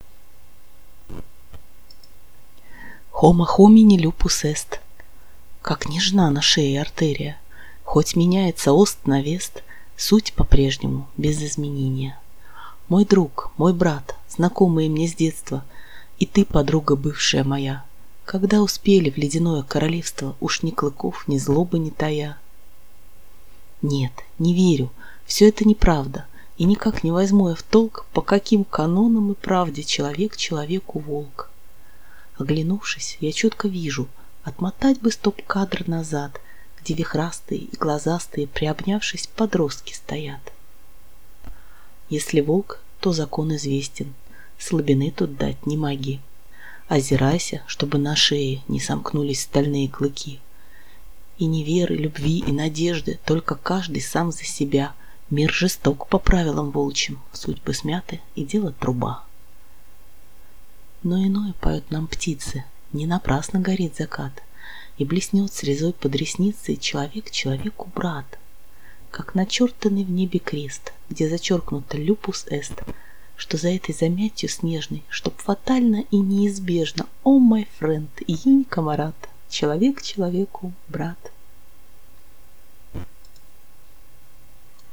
Человек - человеку. Озвучка стиха
В рамках проекта "Ведьмина служба доставки откликов" - озвучка стиха "Человек - человеку" Татьяны Нещерет и ассоциативный арт к нему же. 🙂